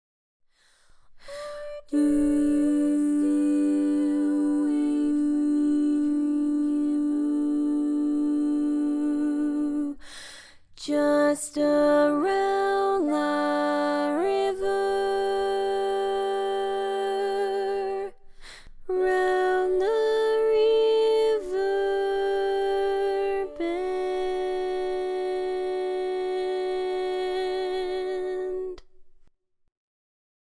Key written in: D Major
Type: Female Barbershop (incl. SAI, HI, etc)